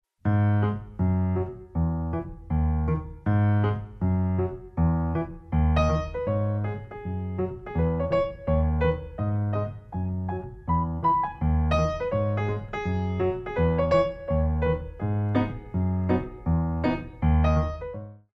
Piano selections include:
Frappé